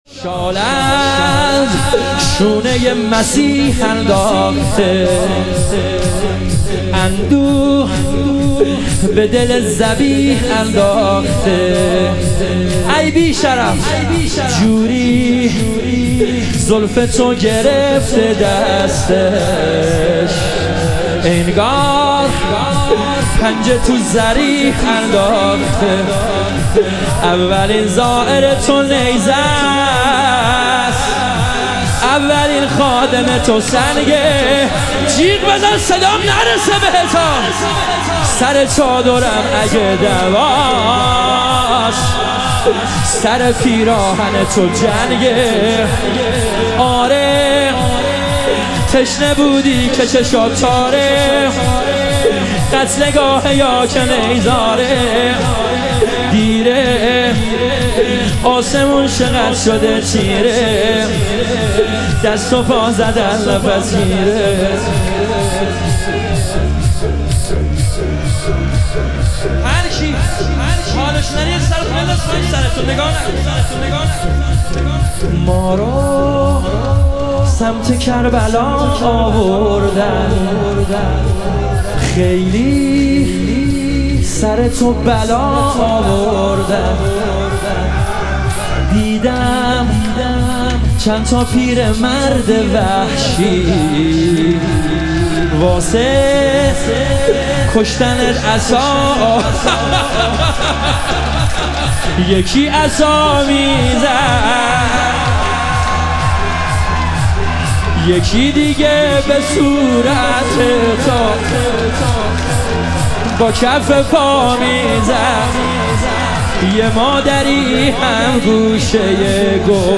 شهادت امام کاظم علیه السلام - لطمه زنی